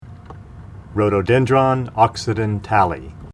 Pronunciation Cal Photos images Google images
Rhododendron_occidentale.mp3